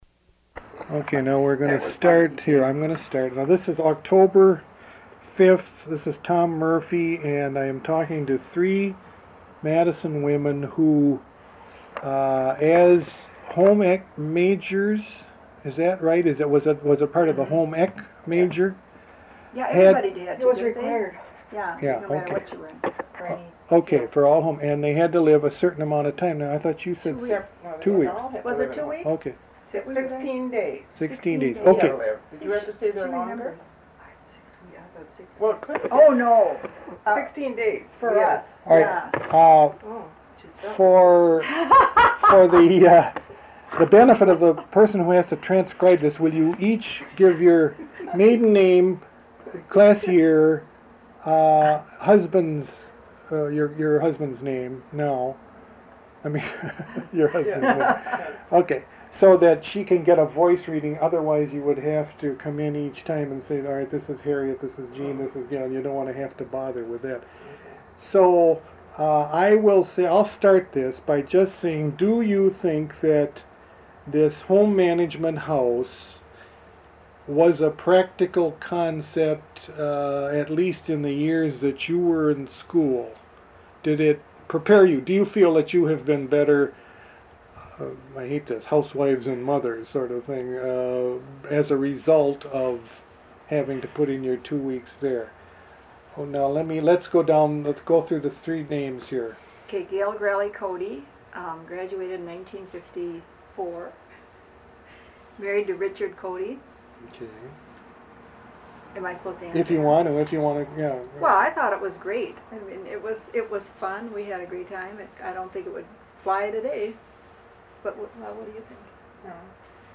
Oral History Interview: Home Management House (0396)